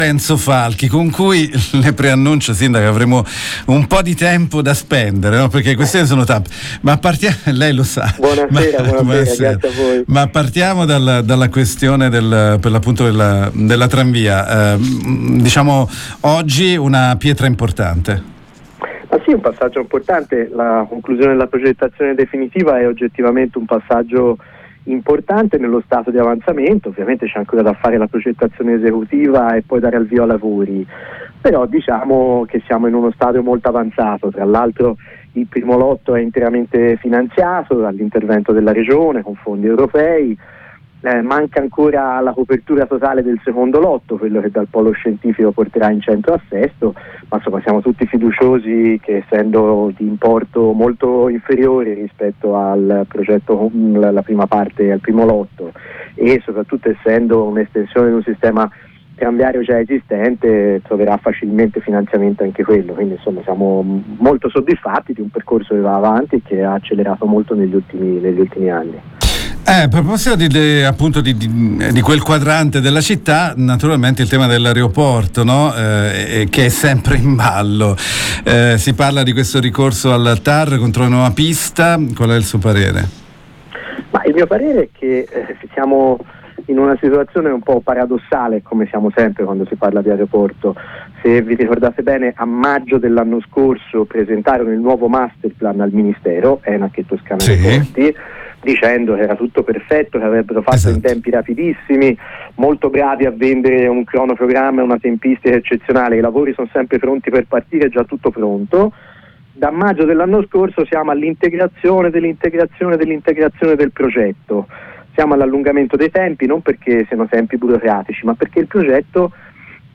Lo ha detto il sindaco di Sesto fiorentino Lorenzo Falchi, lo abbiamo intervistato